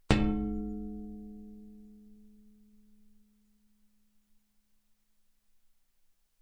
描述：在Baschet Sound Sculpture中演奏弓，木和金属的声音